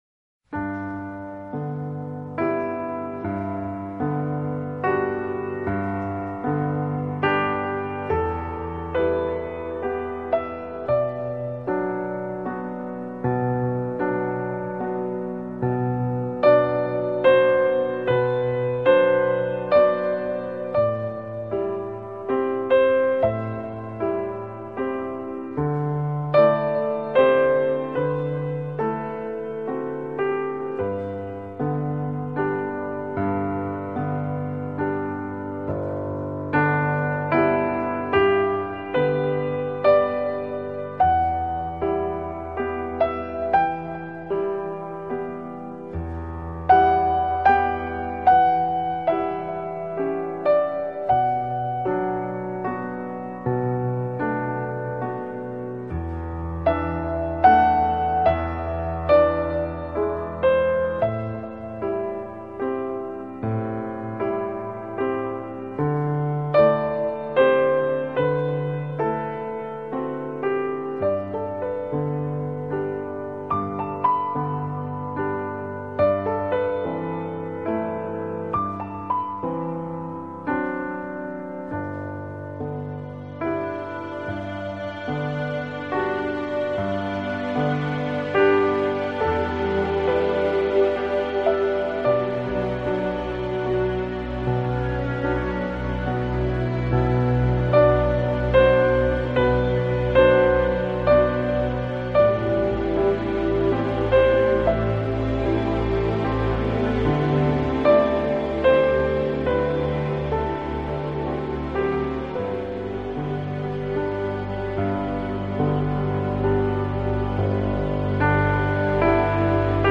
【钢琴纯乐】